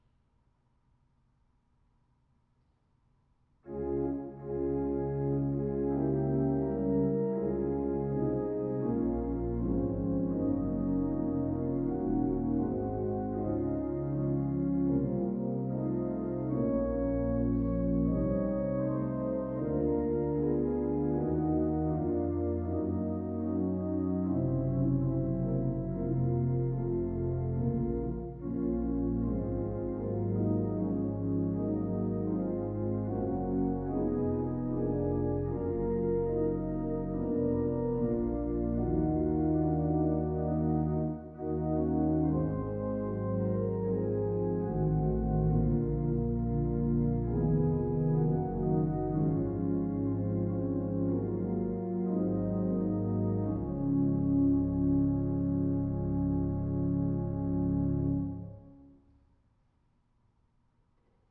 Voicing: Organ